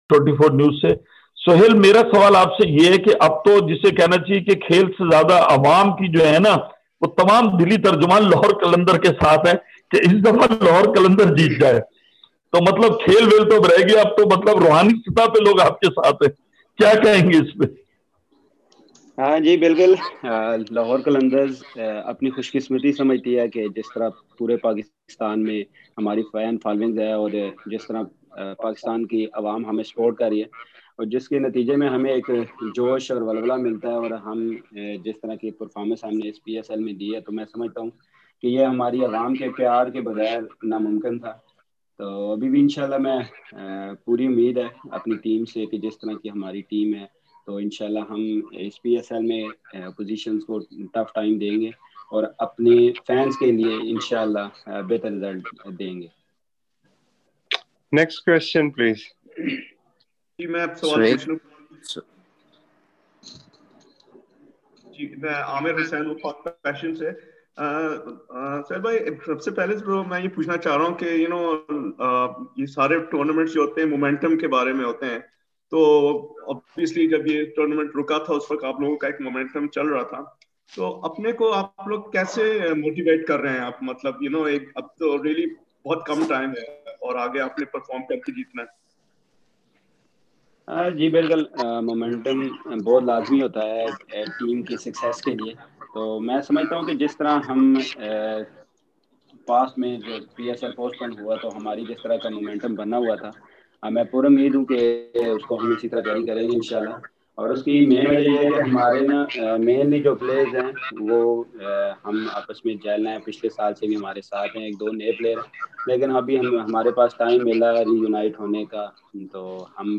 Lahore Qalandars and Peshawar Zalmi captains hold pre-match virtual media conferences
Lahore Qalandars captain Sohail Akhtar and Peshawar Zalmi captain Wahab Riaz held pre-match virtual media conferences with the local media today.